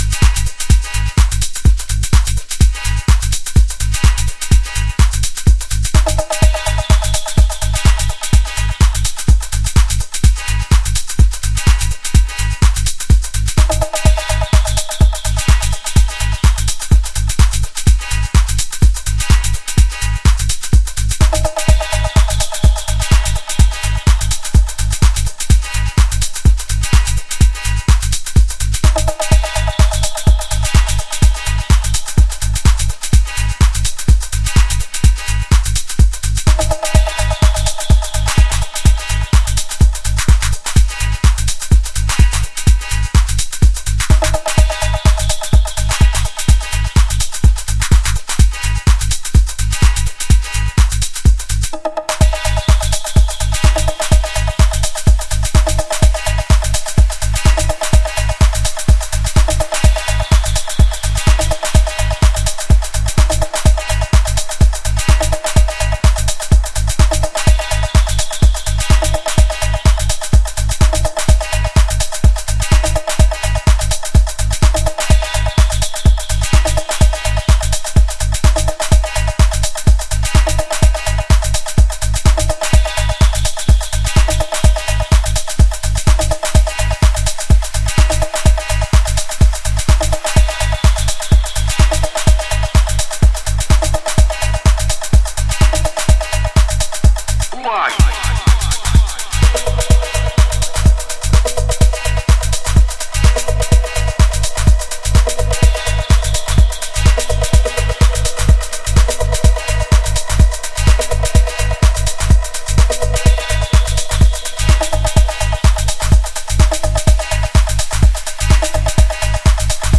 House / Techno
ハウスとテクノの間をいくようなトラックス。